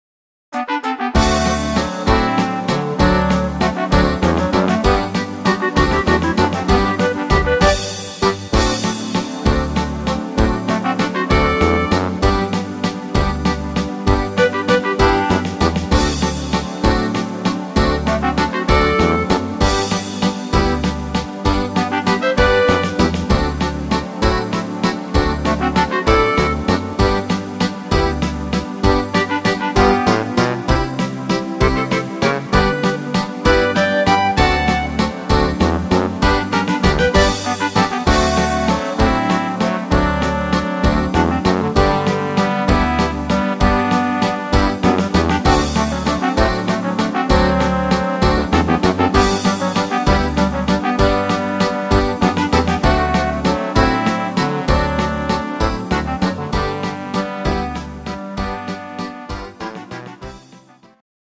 instr. Akkordeon